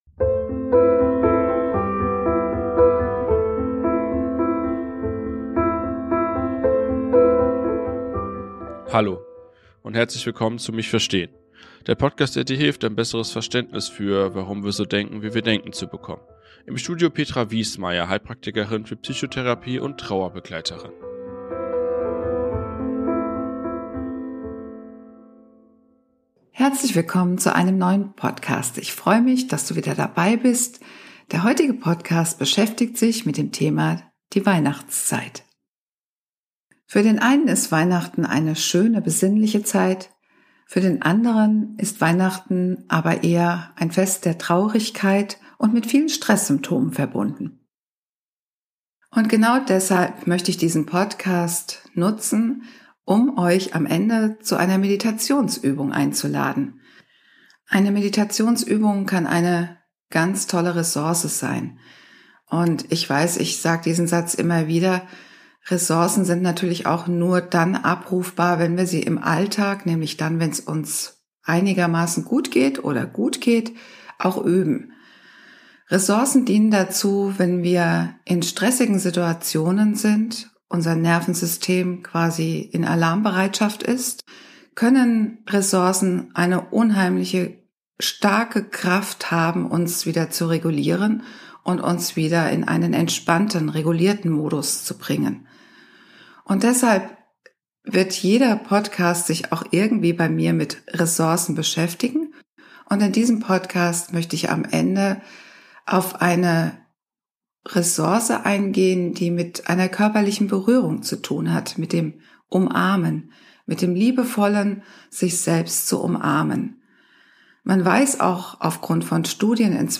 Das besondere Highlight ist am Ende eine Meditationsübung, die Ihnen dabei hilft in der Umarmung Geborgenheit und Wärme zu finden.